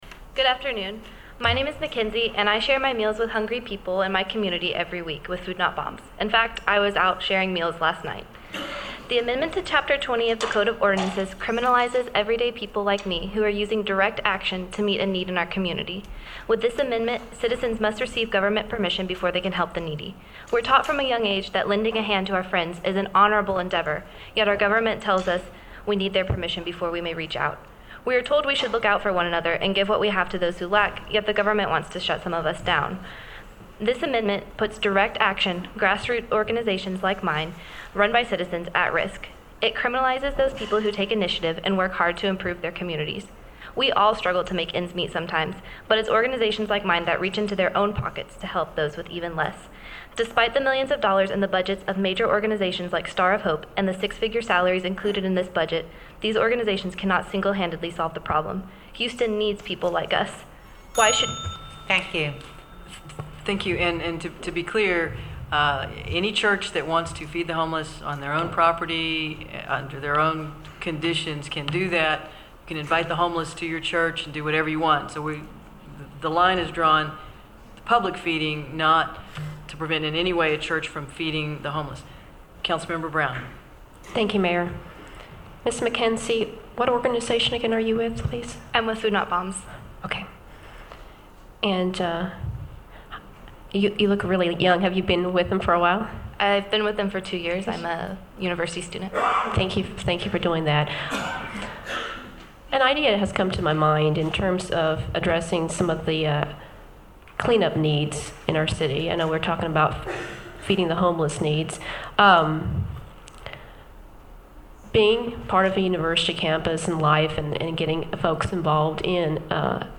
Houston City Council Hearings on Anti Food Sharing Ordinance
Houstonians including many Food Not Bombs volunteers speak out against the Amendment to Chapter 20 of Code of Ordinances.